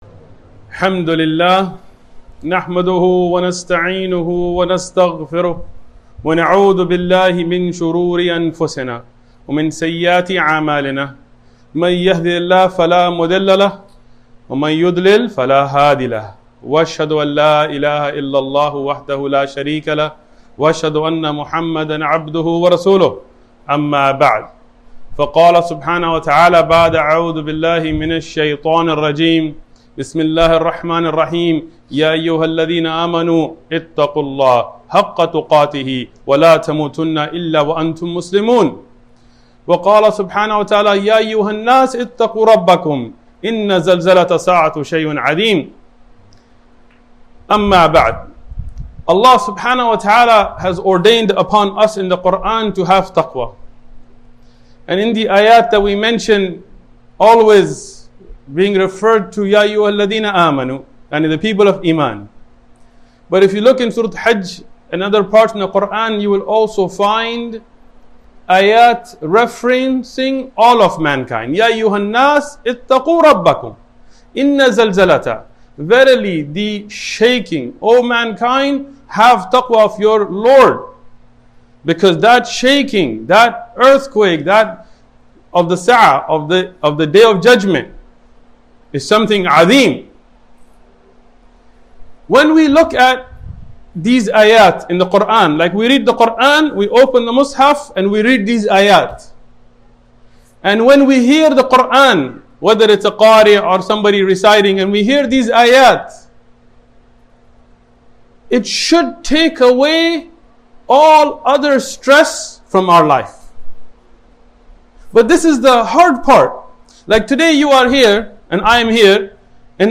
1 Out of 1000 Will Enter Jennah ｜ Powerful Jummah Khutbah